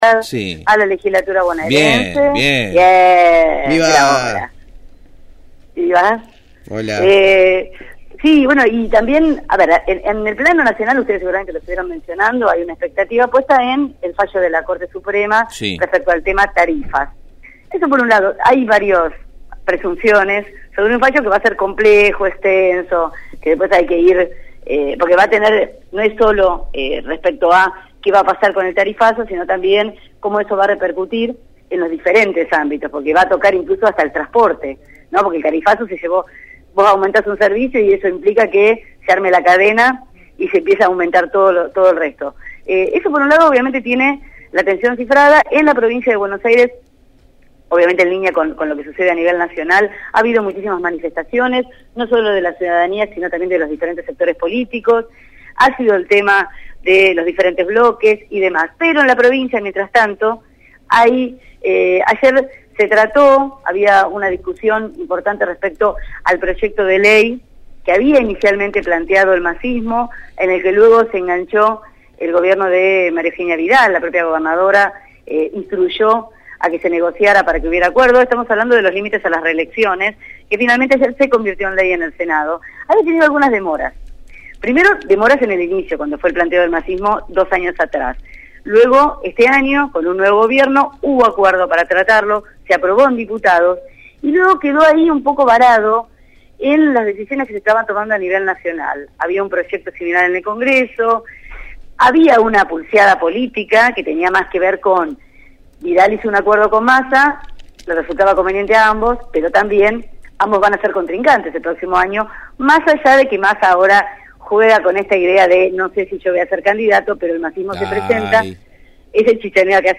En esta oportunidad se refirió a la sanción de la ley que limita a una la cantidad de reelecciones a las que podrán aspirar los intendentes, concejales y legisladores de la provincia de Buenos Aires.